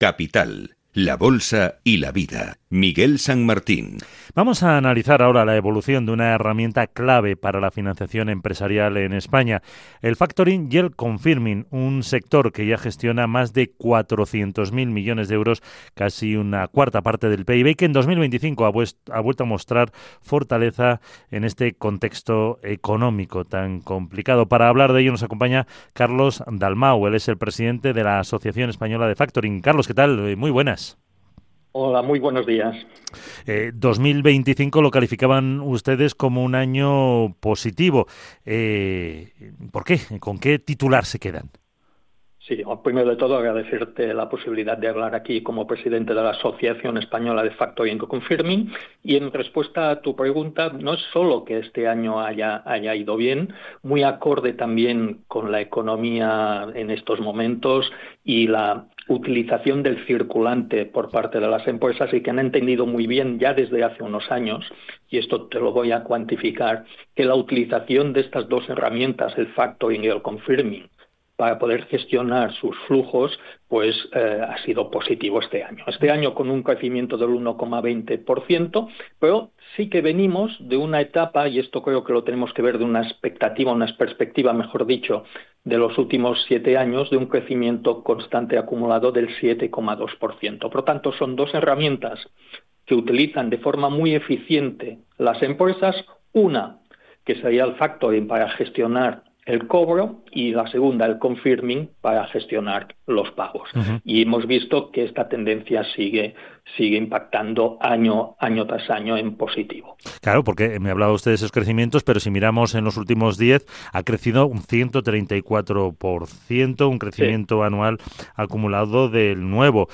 Entrevista AEF en Capital Radio - Asociación española de Factoring